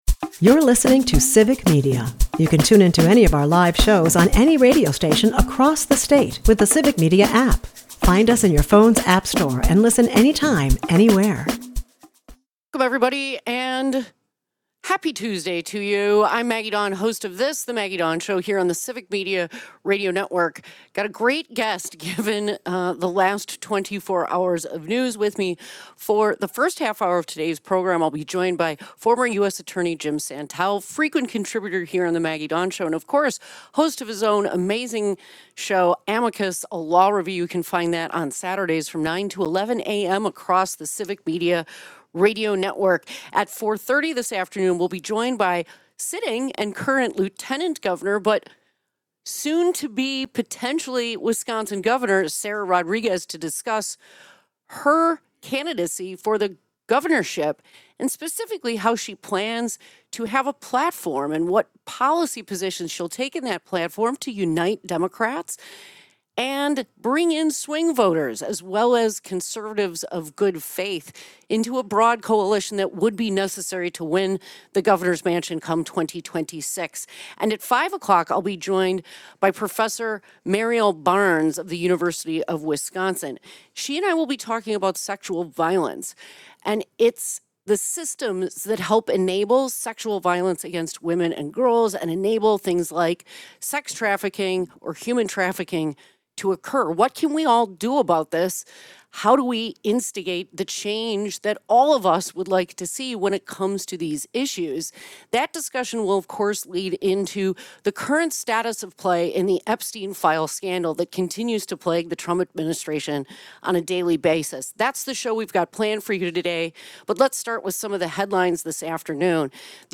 Later, Lieutenant Governor Sara Rodriguez joins the show to officially announce her 2026 gubernatorial run. Drawing on her healthcare background and bipartisan credentials, Rodriguez outlines a platform focused on expanding healthcare access, addressing housing shortages, and supporting small businesses.